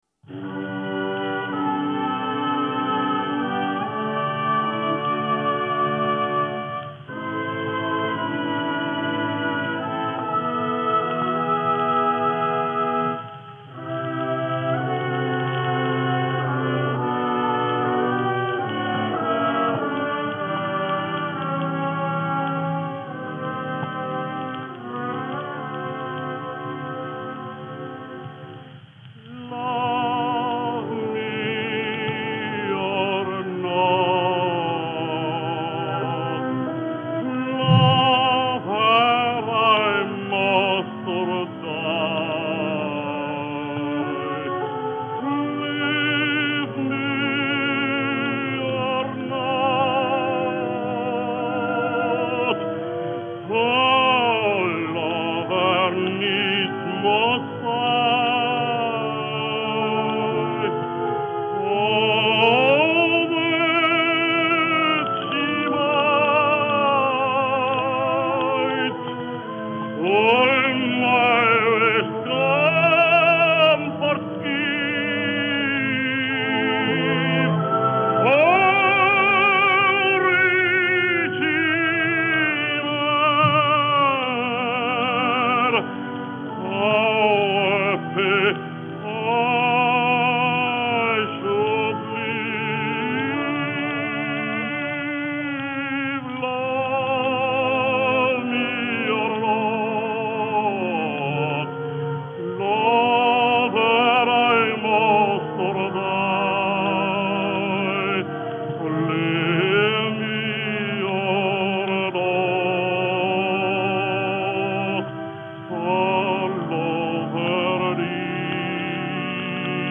Жанр: Vocal